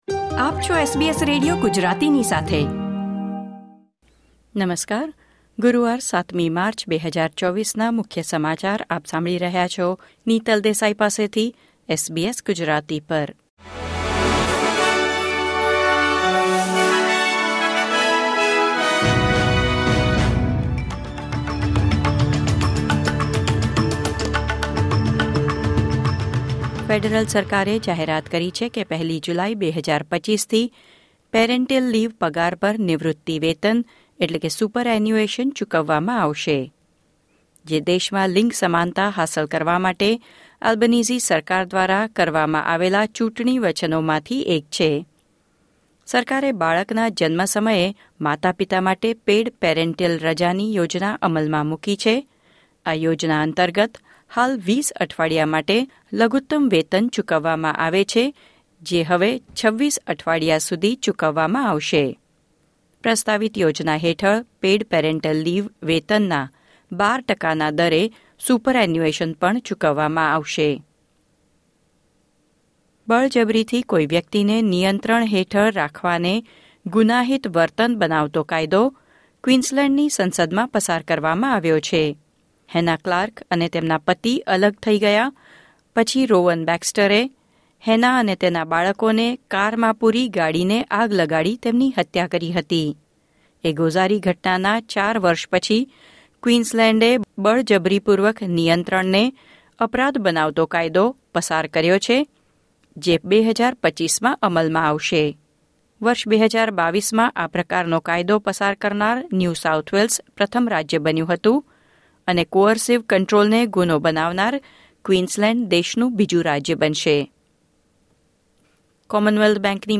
SBS Gujarati News Bulletin 7 March 2024